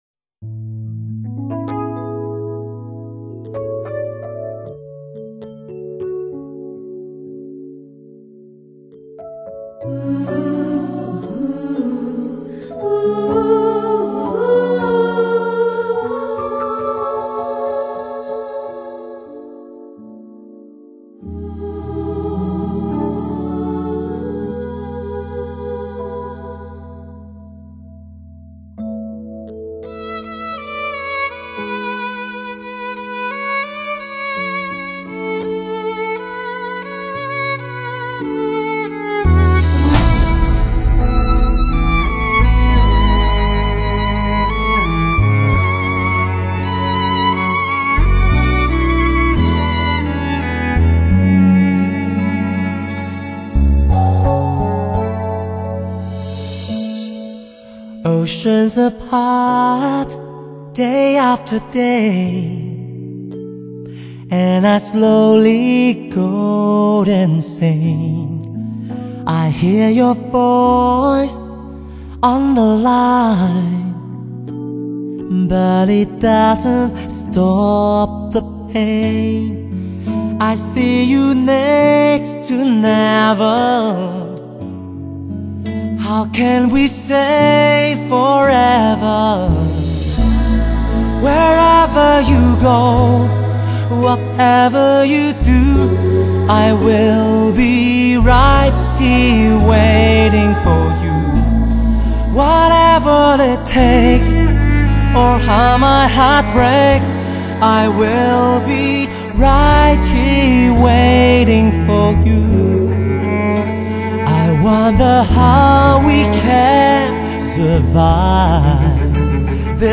来处灵魂的动容情感 浑厚脱俗的嗓音